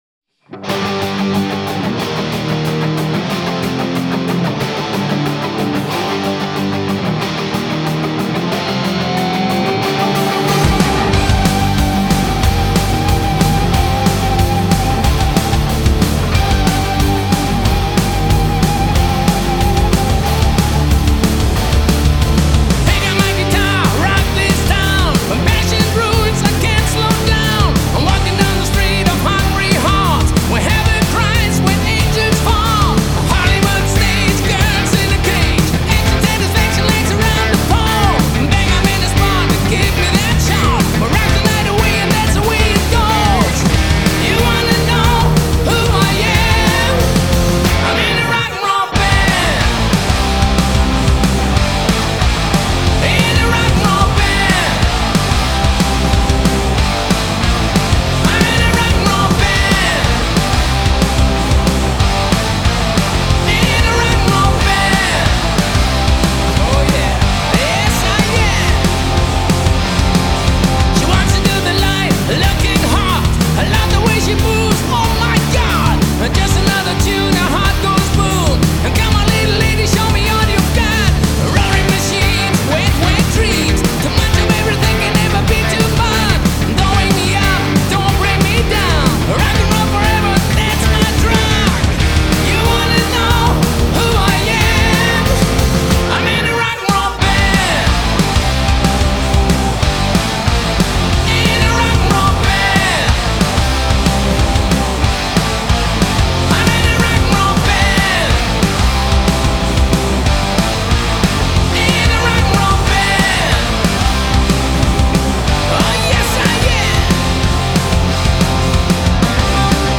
Rock 2016